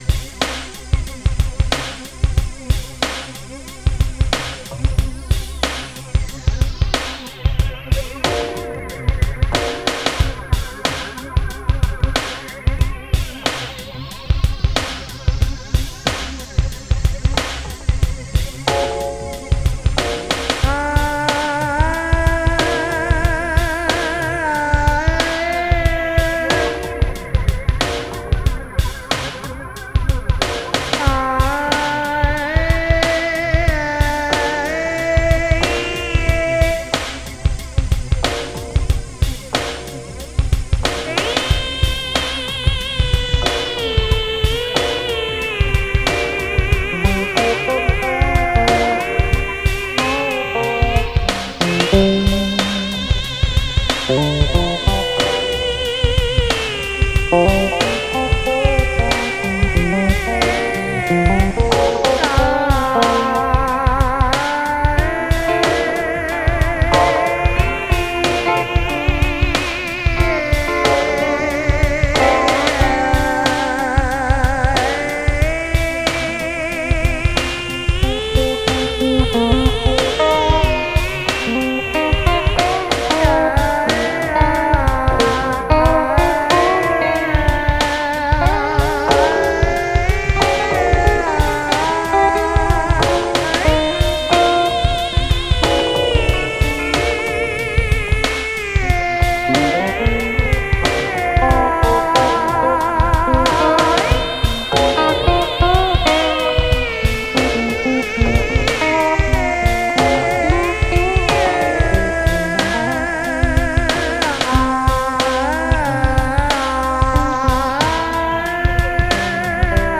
Tempo: 48 bpm / Datum: 22.06.2015